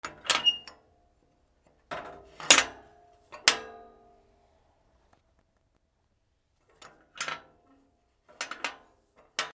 金属のノブ 門
/ K｜フォーリー(開閉) / K05 ｜ドア(扉)
『カチャキィ』